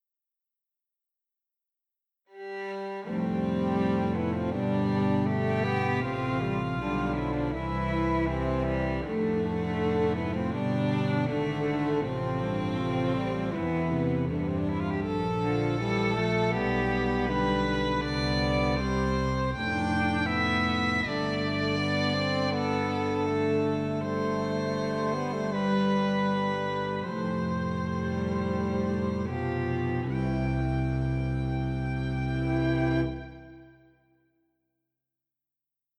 バイオリン、ビオラ、チェロ、コントラバスの四重奏です。